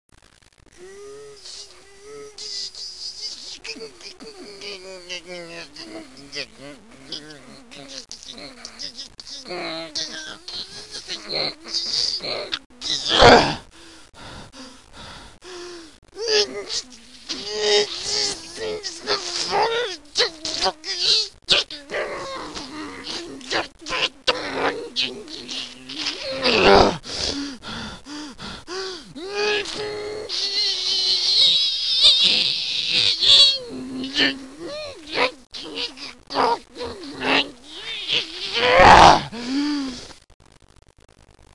Sound effects
ggnnnnnngggnn.wma